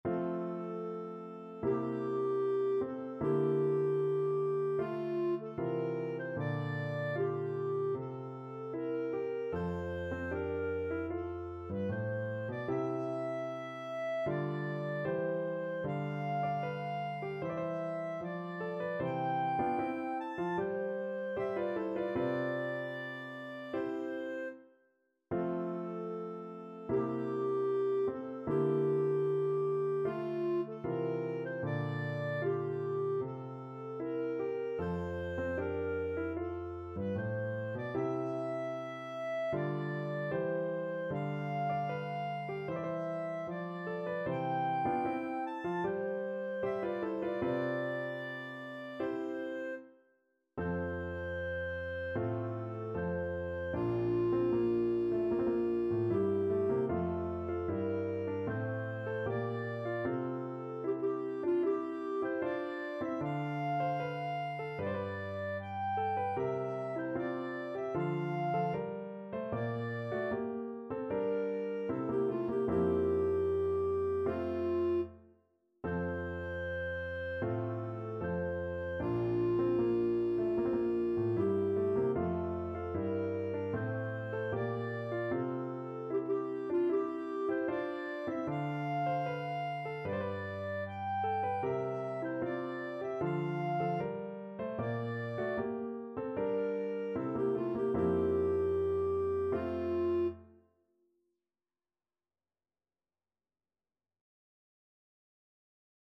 Classical Mozart, Wolfgang Amadeus March from Idomeneo, Act 3 Clarinet version
Clarinet
4/4 (View more 4/4 Music)
F major (Sounding Pitch) G major (Clarinet in Bb) (View more F major Music for Clarinet )
Andante =76
march_idomeneo_act3_CL.mp3